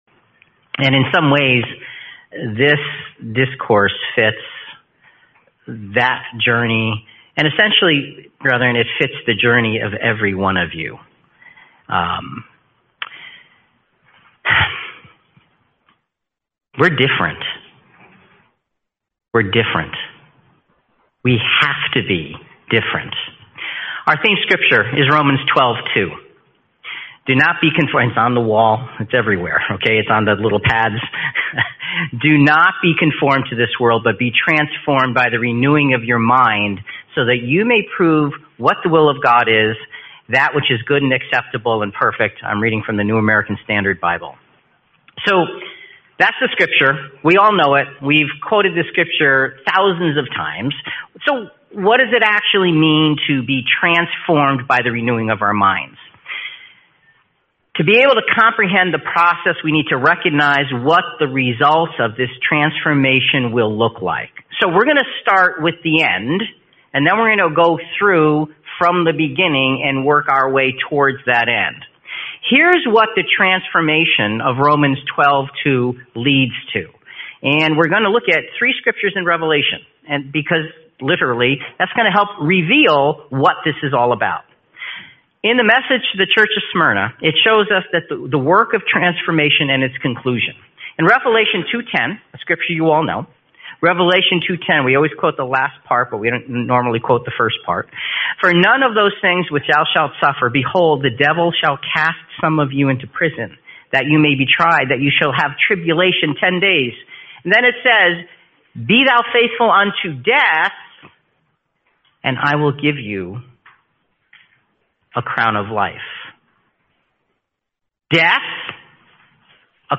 Series: 2026 Phoenix Convention